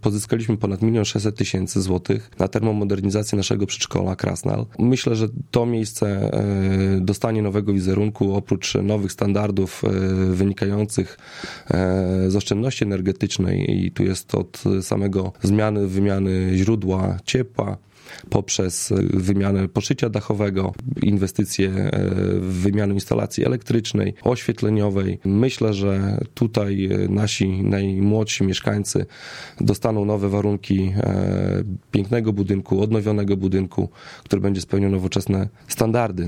Mijający 2019 rok podsumował w piątek (27.12) na antenie Radia 5 Karol Sobczak, burmistrz Olecka.